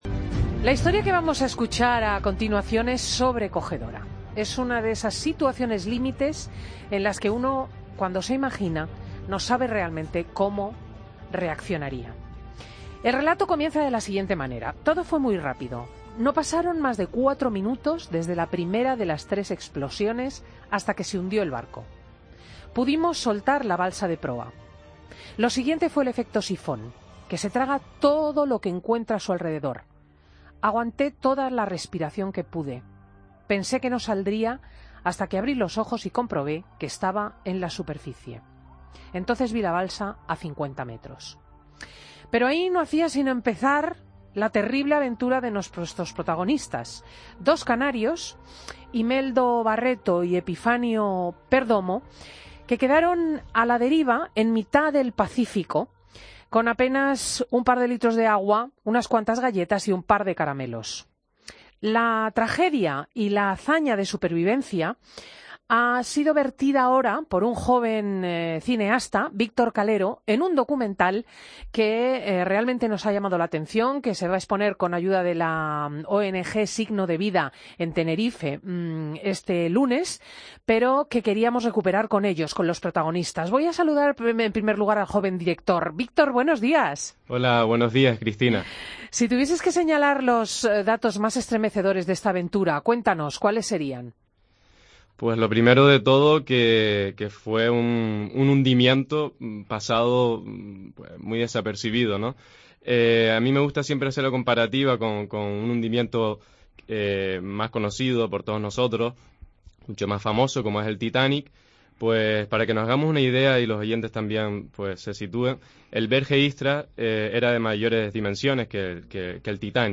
Los naúfragos del Berge Istra han contado en Fin de Semana cómo sobrevivieron 19 días a la deriva hace ahora 40 años.